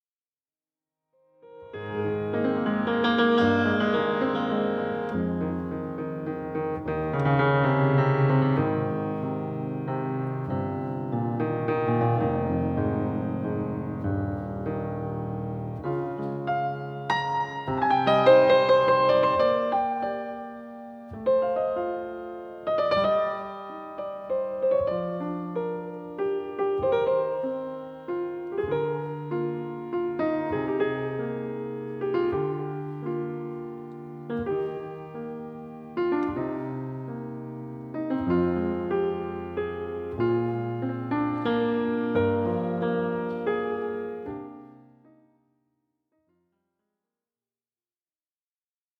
Entre grégorien et gospel, le piano chante Marie.